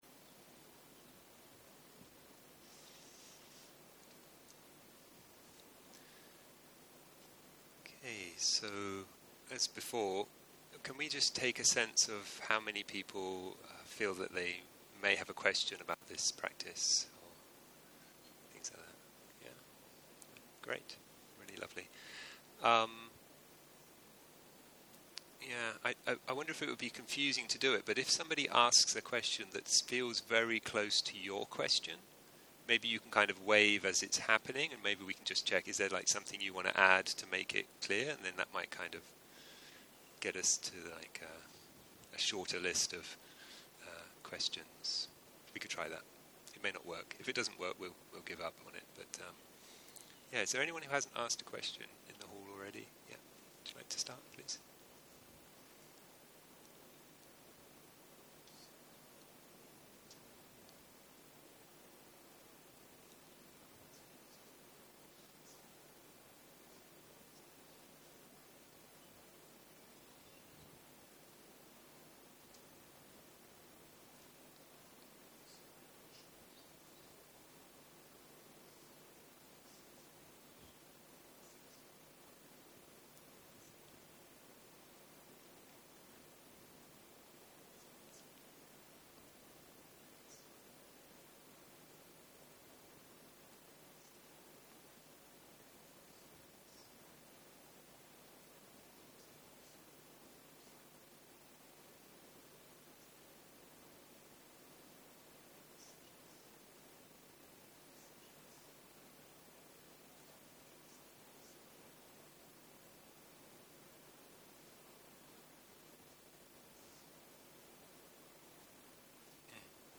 12.04.2023 - יום 6 - בוקר - שאלות ותשובות - הקלטה 19